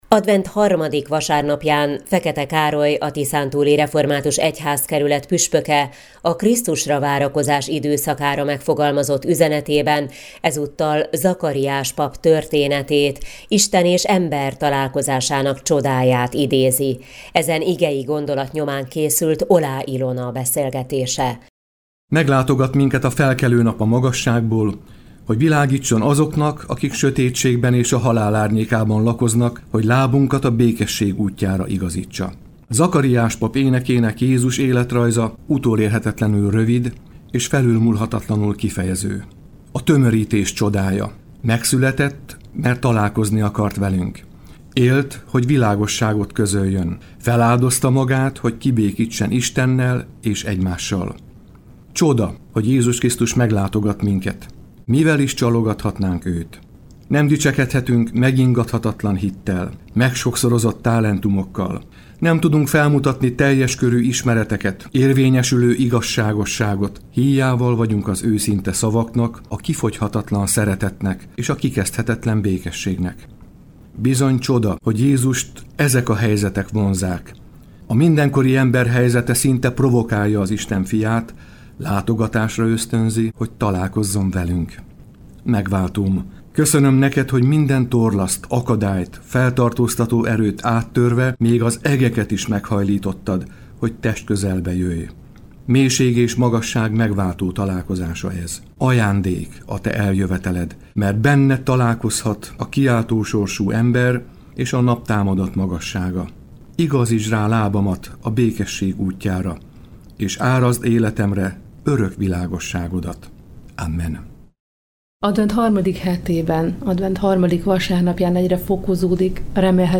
beszélgetése az Európa Rádióban